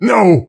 panelopen01.ogg